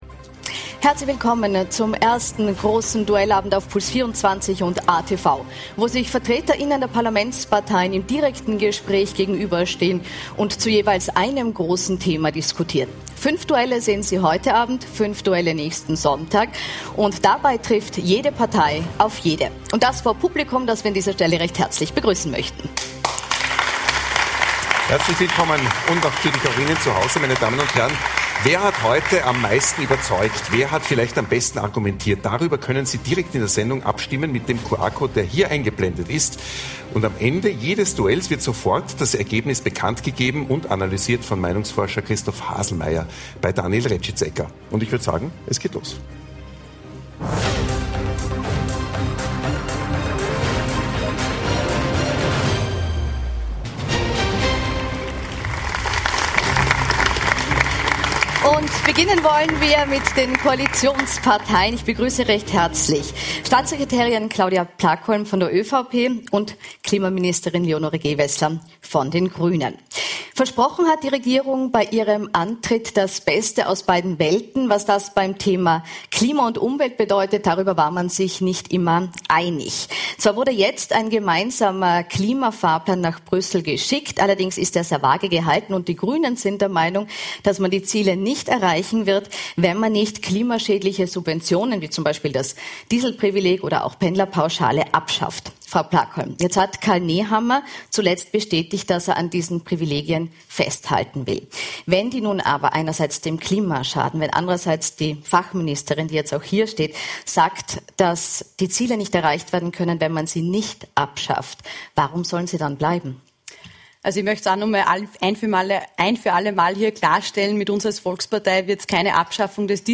Duellabend mit Plakolm, Herr, Amesbauer, Gewessler, Schellhorn/Brandstätter